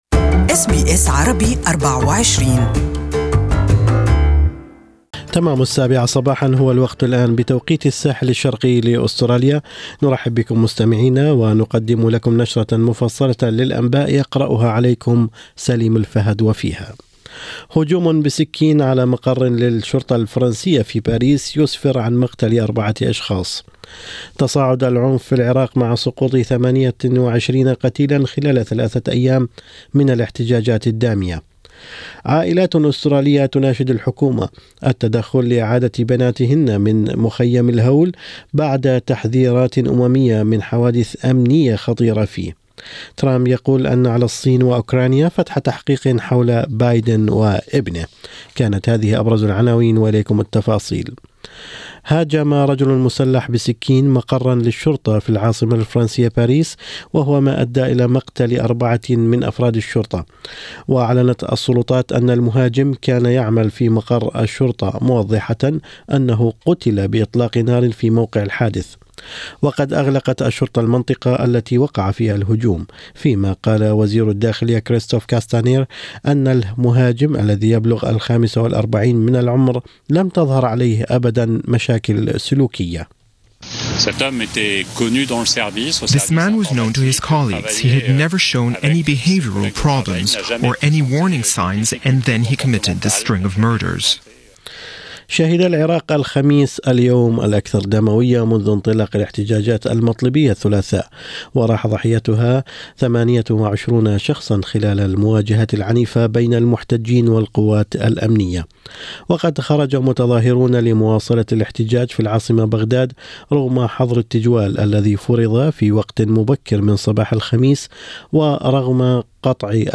In two exclusive interviews by the SBS Arabic24 , the two families made their appeals.